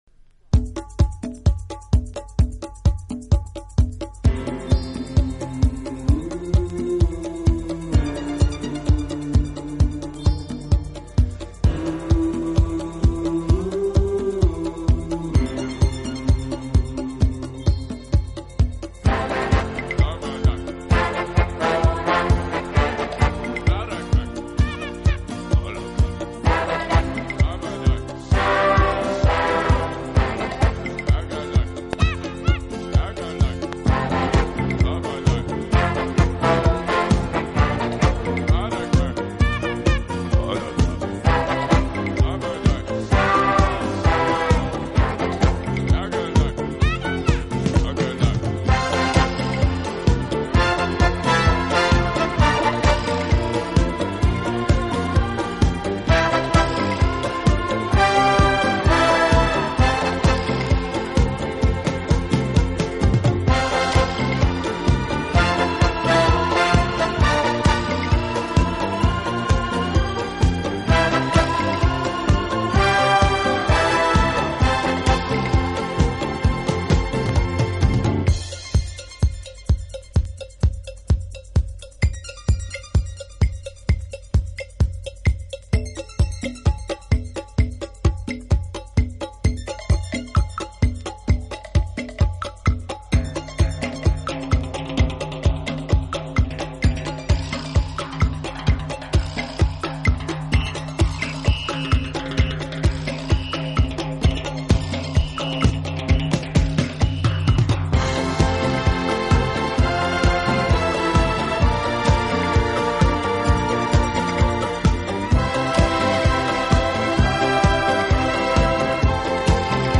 【轻音乐】
有动感，更有层次感；既有激情，更有浪漫。